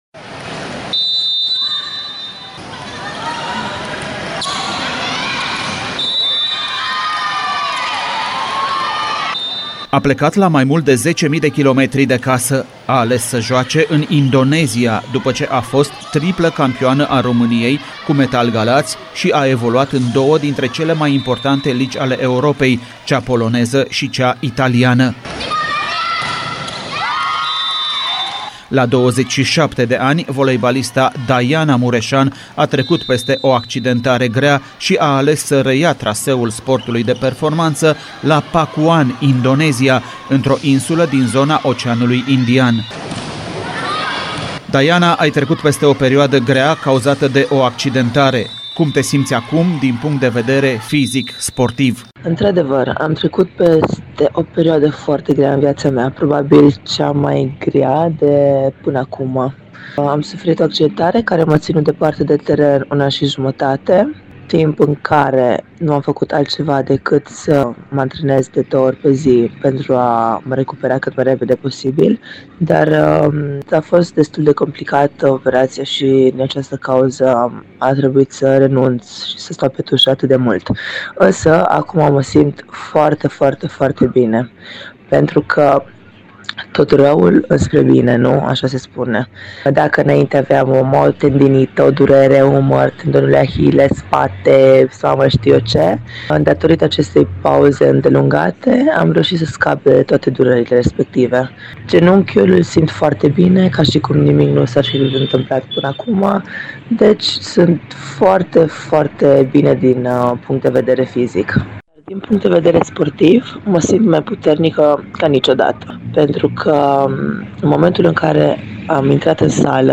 Un interviu pe larg